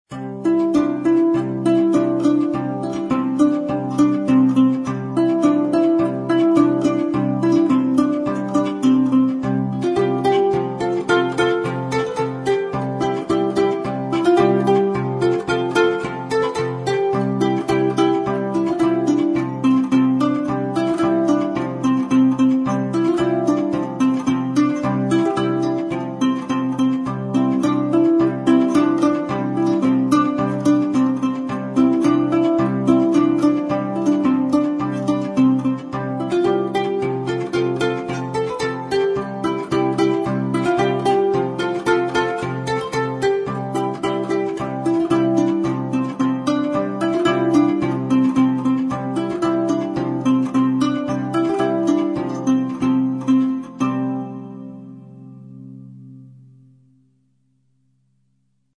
• Главная » Файлы » Музыкальные произведения » Кюи
Жетыген